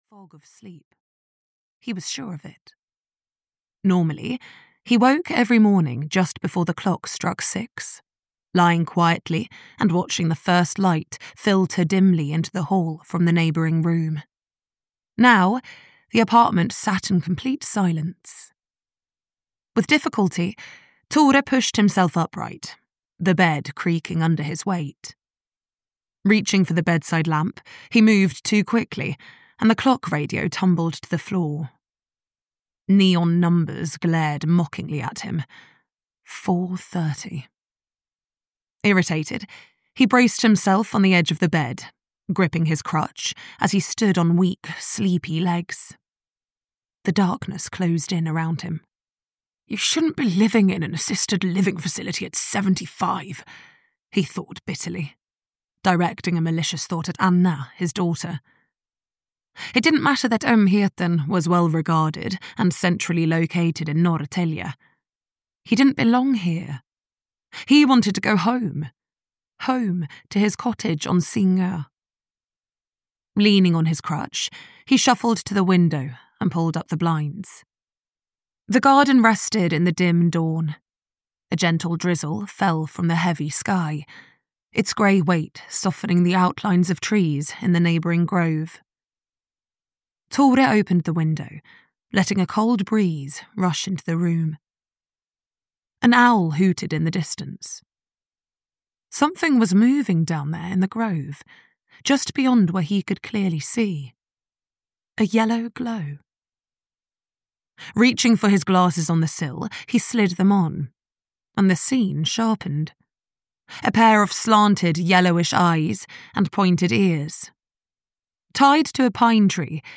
The Things We Leave Behind (EN) audiokniha
Ukázka z knihy